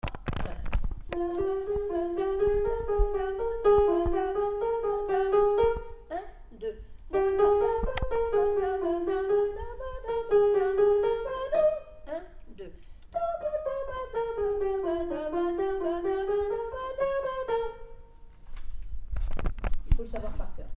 sop mes 41 a 52